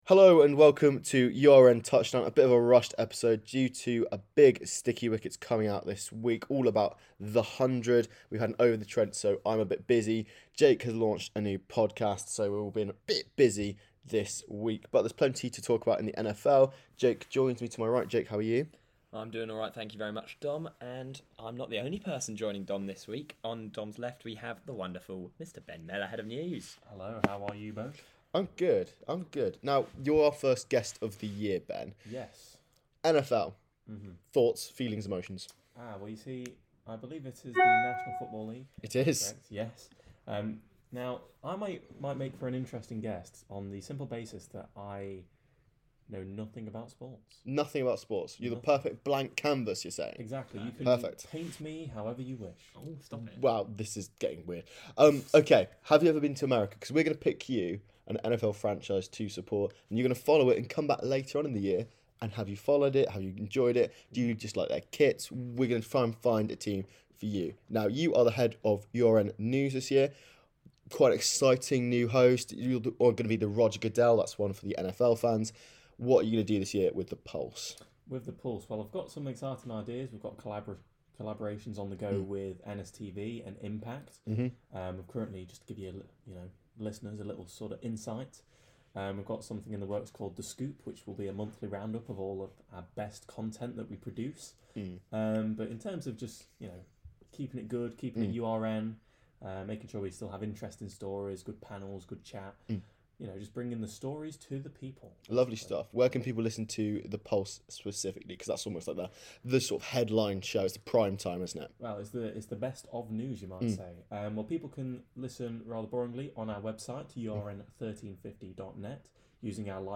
WE KNOW THE MIC ISN'T GREAT!!!
WE ALSO ARE AWARE THE POST-MATCH MINUTE IS HAS AN AUDIO ISSUE, WE DO APOLOGISE BUT WE ARE UNABLE TO RESOLVE THE ISSUE.
The mic quality wasn't great we are working on solving that issue